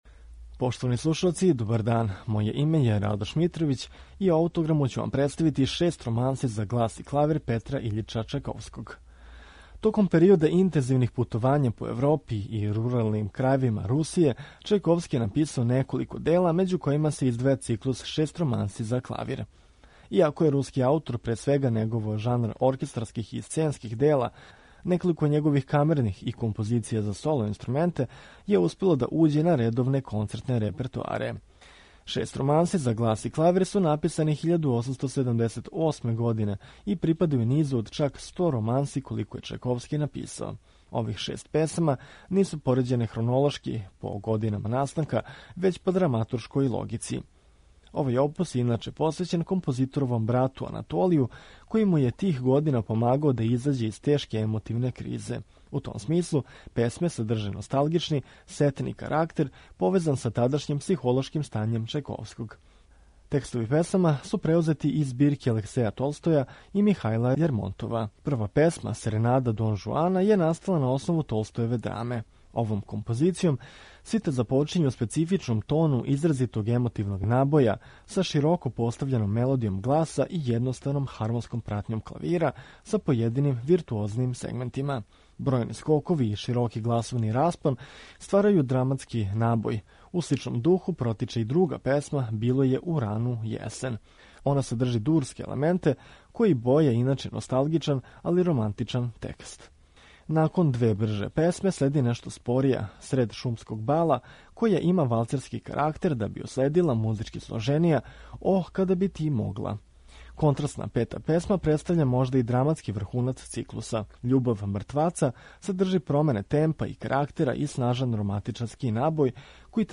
Петар Чајковски - Шест романси за глас и клавир